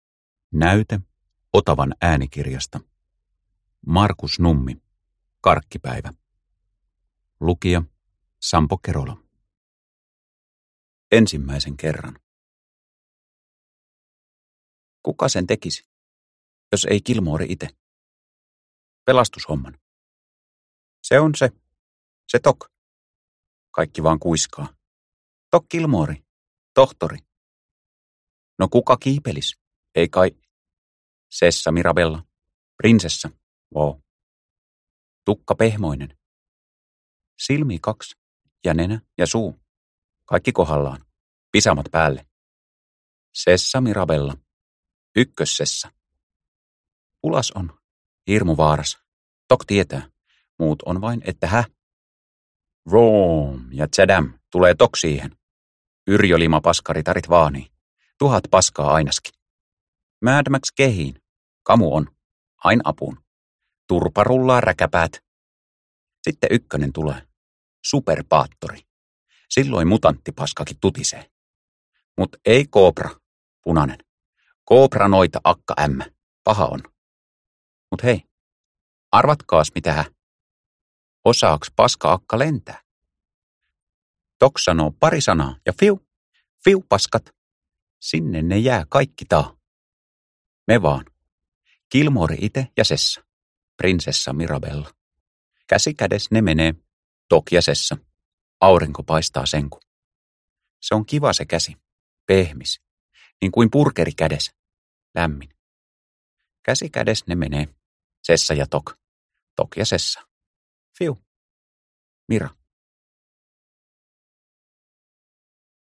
Karkkipäivä – Ljudbok – Laddas ner